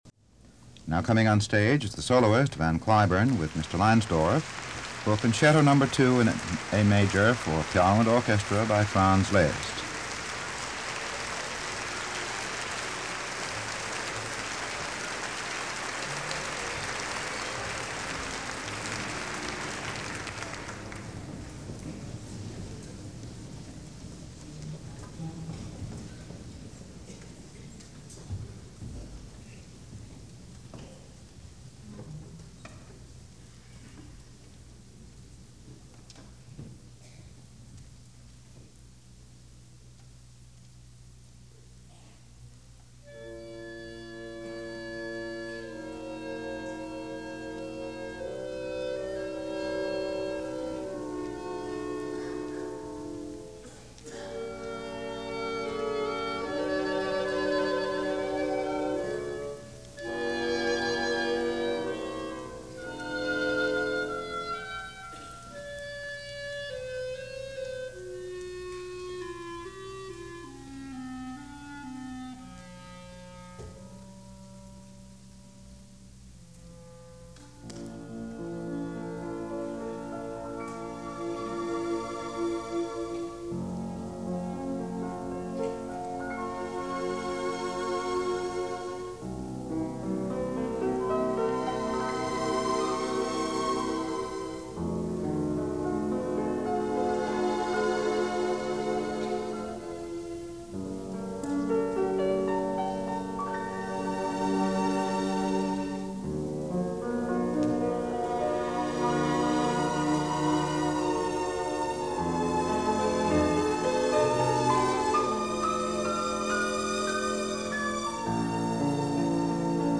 Van Cliburn Plays Music Of Brahms And Liszt In Concert - 1964 - Past Daily Mid-Week Concert: Special Tribute Edition
So as tribute to the passing of this giant in the world of the Performing Arts and a reminder of his magic and deft command of the instrument, here are two concerts, both featuring the Boston Symphony conducted by Erich Leinsdorf and both recorded in 1964. The first one (the top player) features Brahms Piano Concerto No. 1 and the second (the bottom player) features Liszt’s Piano Concerto’s Number 2 and 1 (in that order as they were performed). The Brahms was given at the Tanglewood Festival in July of 1964 and the Liszt was given at a regular concert given in November of 1964.